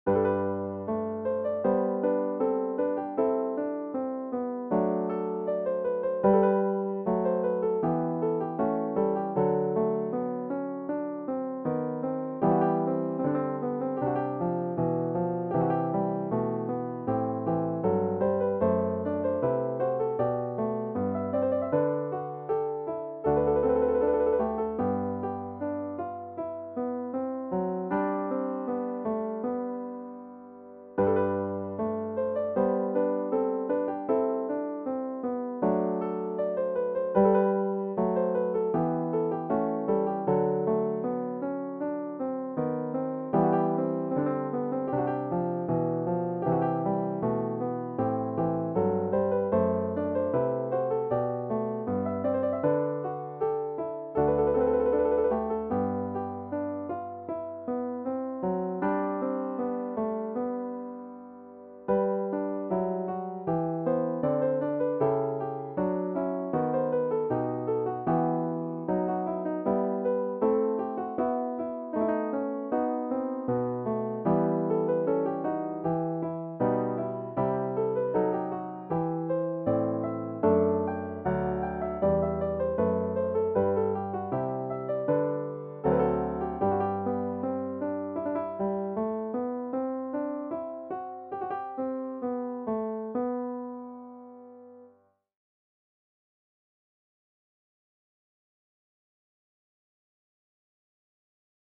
▪ J.S.BACH: Liebster Jesu, wir sind hier (BWV 731) - moje úprava pro klavír → |